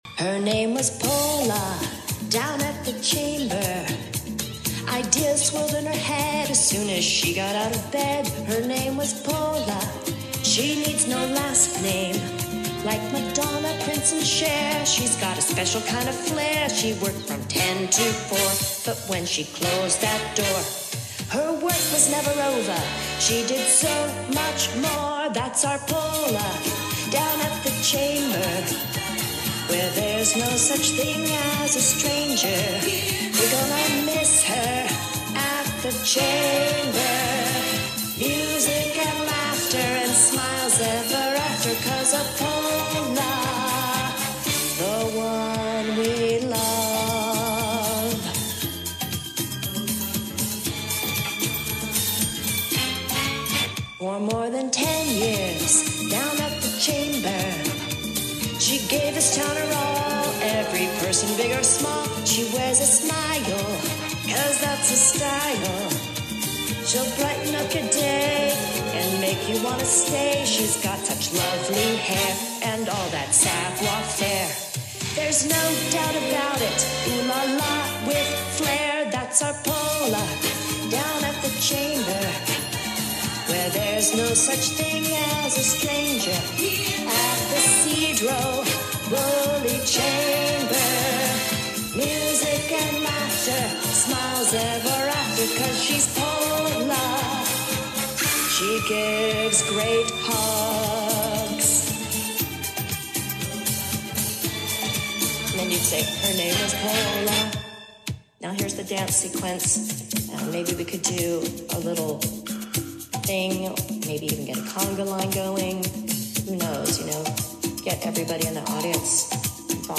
parody song
I settled on my old Panasonic camcorder and a shotgun mic.